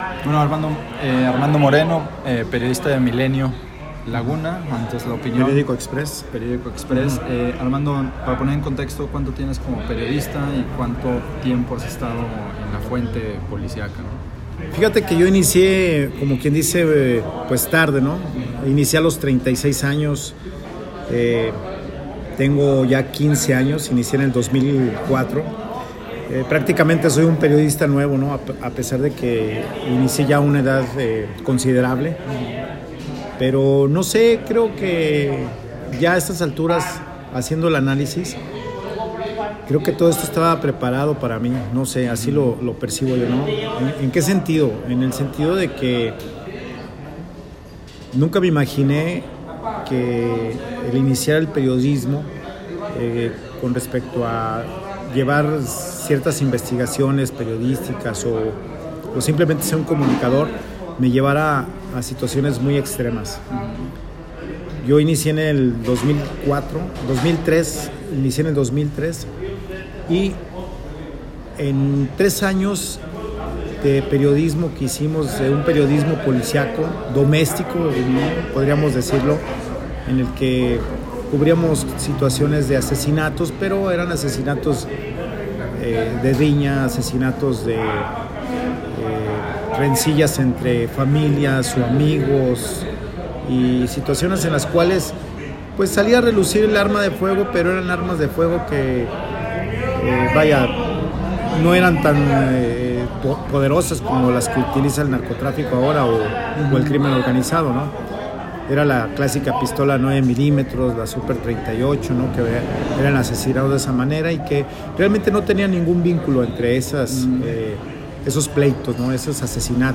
Audio de entrevista